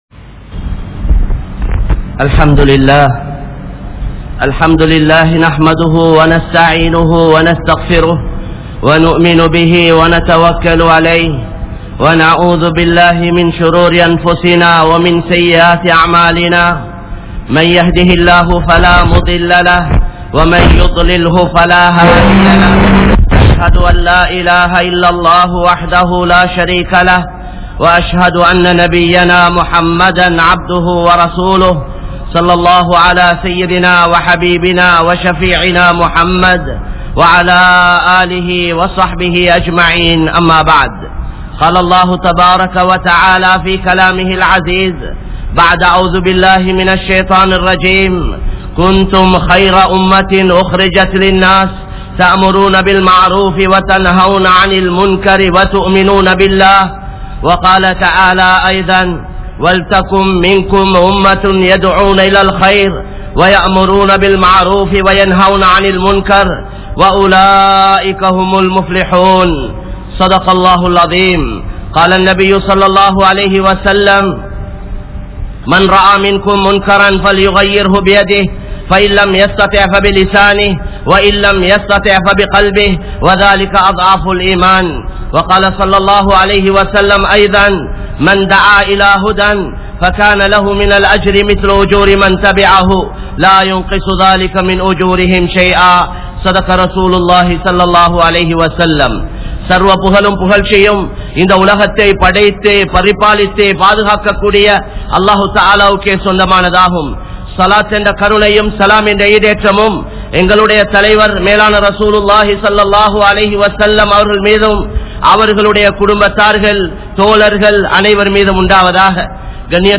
Paavaththai Thadungal (பாவத்தை தடுங்கள்) | Audio Bayans | All Ceylon Muslim Youth Community | Addalaichenai
Kollupitty Jumua Masjith